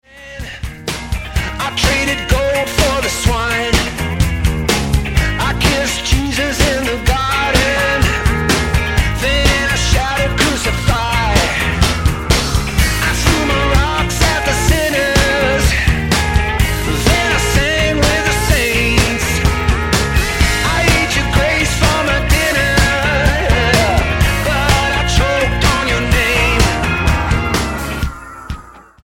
8559 Style: Pop Approach